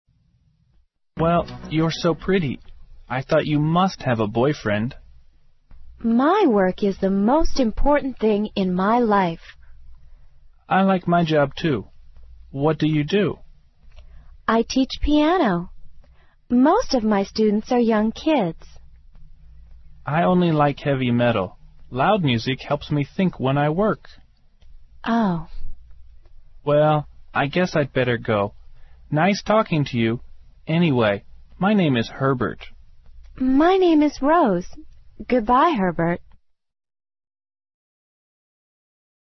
《网络社交口语对话》收集了众多关于网络社交的口语对话，对提高你的口语大有用处，值得你收藏。